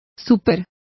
Complete with pronunciation of the translation of premium.